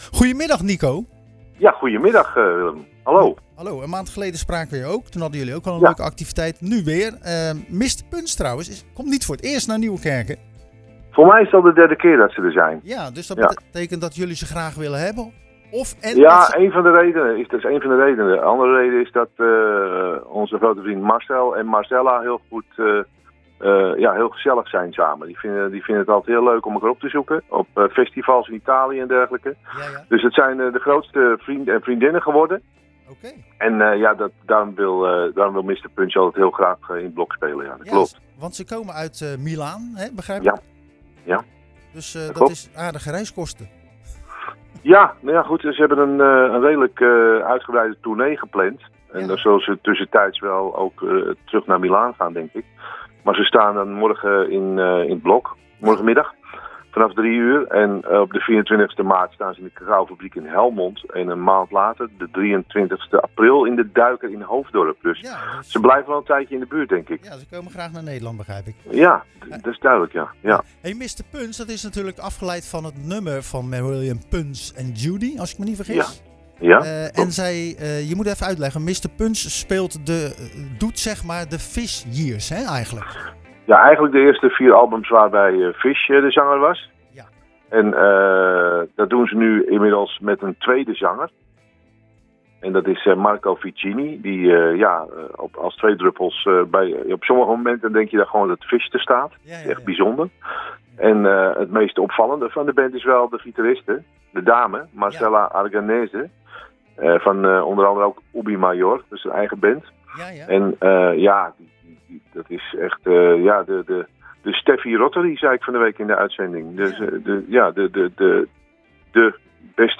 Tijdens de wekelijkse editie stonden we stil bij de ProgFrog activiteiten in het 't Blok in Nieuwerkerk a/d IJssel.